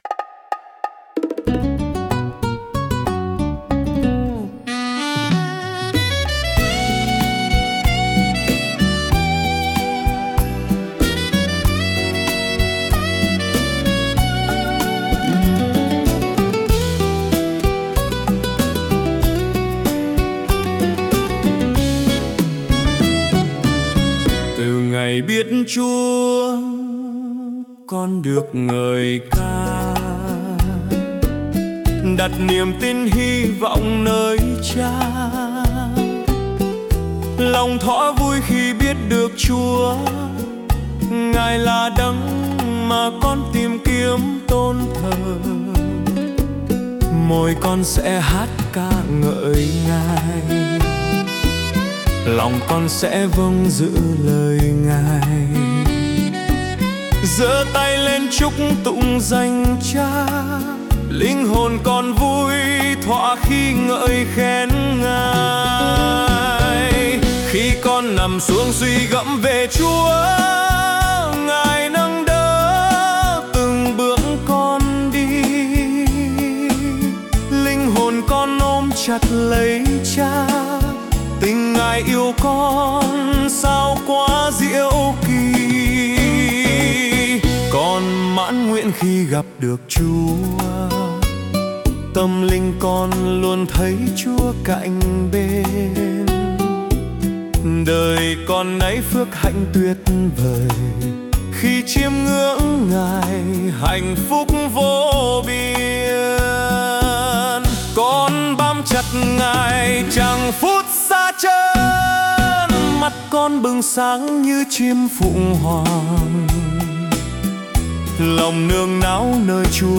Nhạc AI